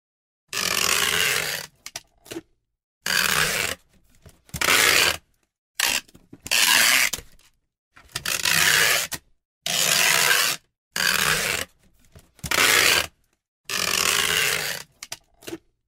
Tiếng kéo băng Keo, băng Dính đóng gói hộp carton đóng hàng
Thể loại: Tiếng đồ vật
Description: Hiệu ứng âm thanh chân thực mô phỏng tiếng kéo băng keo khi đóng gói hàng hóa.
tieng-keo-bang-keo-bang-dinh-dong-goi-hop-carton-dong-hang-www_tiengdong_com.mp3